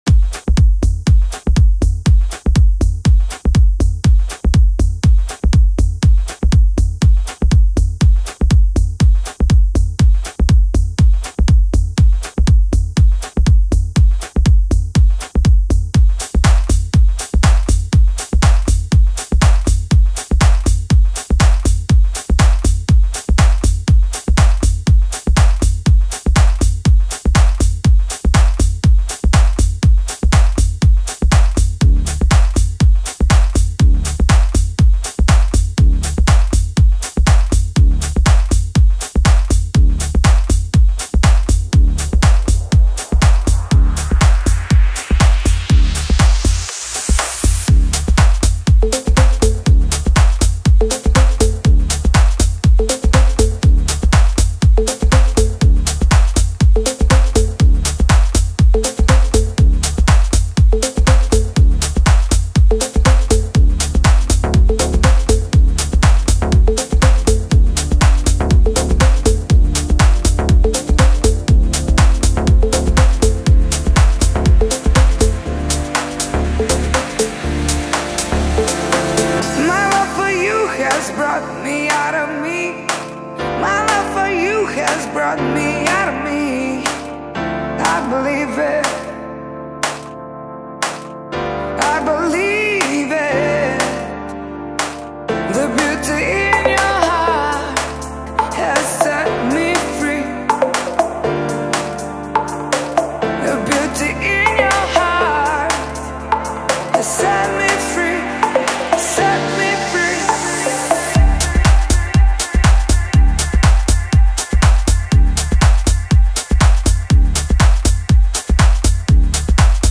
Electronic, Lounge, Chill Out, Chill House
House & Chill Sounds To Groove & Relax